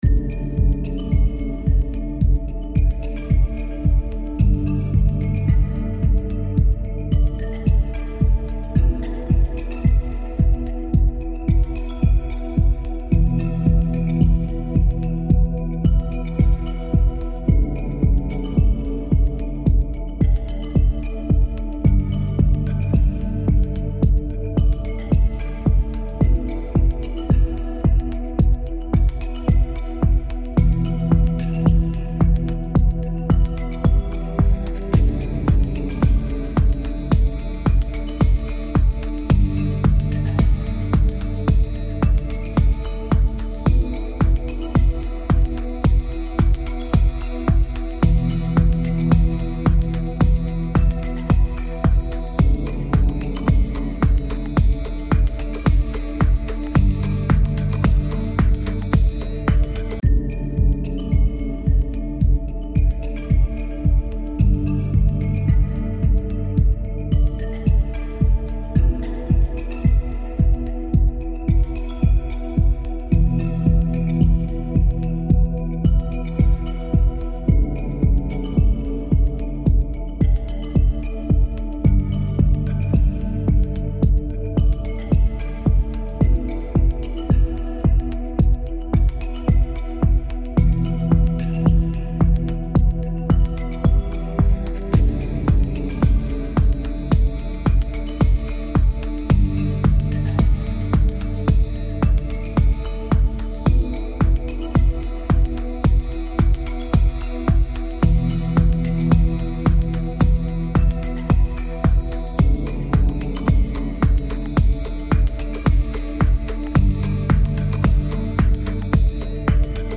Ambient_Ibiza_Lounge.mp3